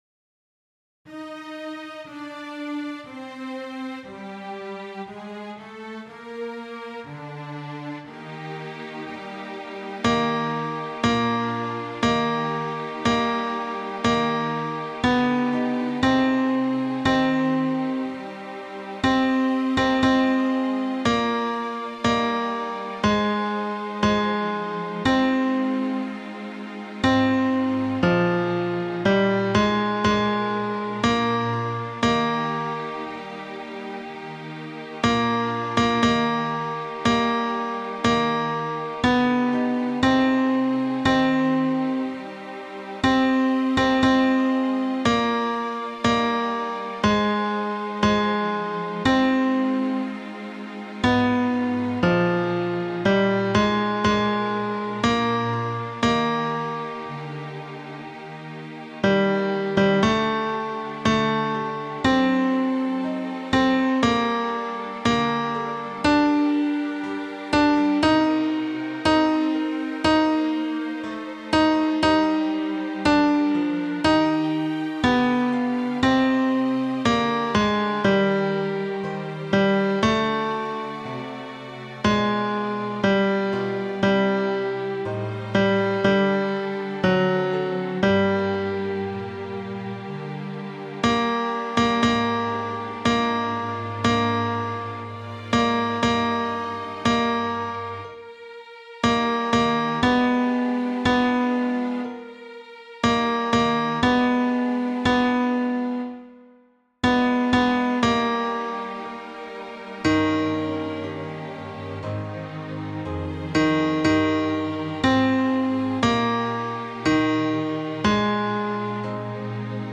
Tenor I
Mp3 Música